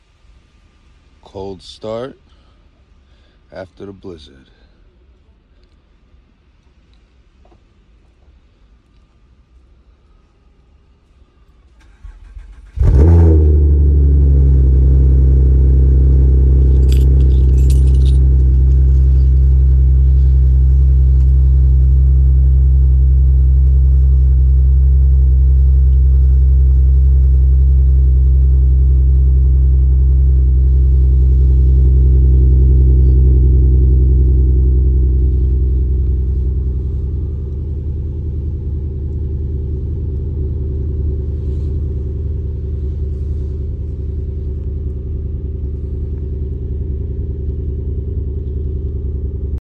Best & Greatest Cold Start sound effects free download
Best & Greatest Cold Start Sounding Acura TL AWD 3.7 Complete EndLessRPM Exhaust w J Pipe.